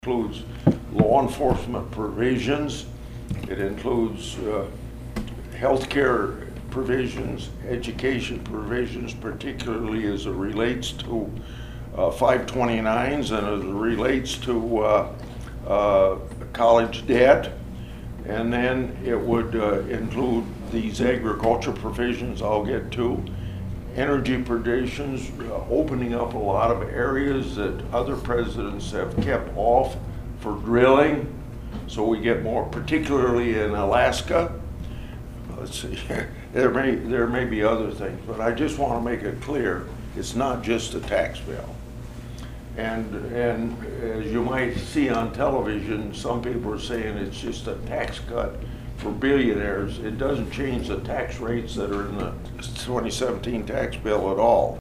(Atlantic) Senator Chuck Grassley met with members of the Cass County Farm Bureau and others in the Ag community at TS Bank in Atlantic Friday afternoon.